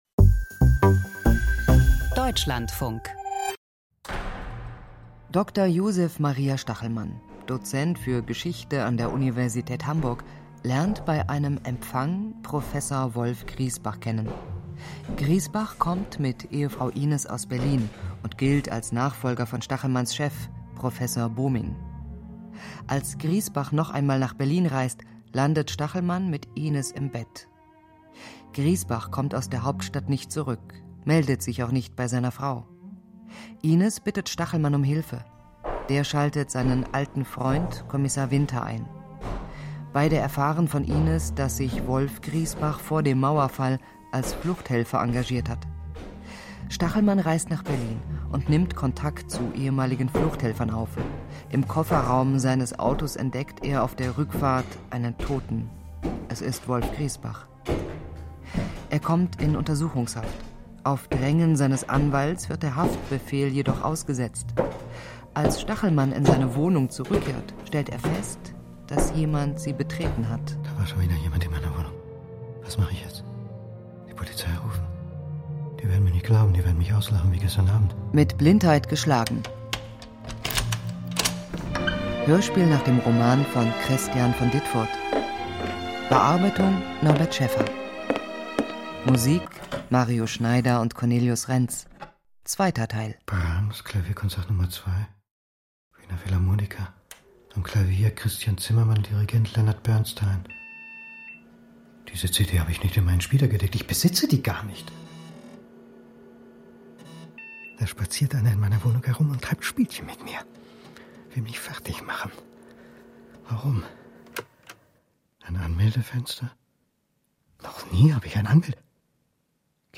Krimi Hörspiel Krimi-Reihe: Stachelmanns zweiter Fall Mit Blindheit geschlagen (2/2) 55:56 Minuten Als sein Konkurrent verschwindet, wird Historiker Dr. Stachelmann selbst zum Hauptverdächtigen.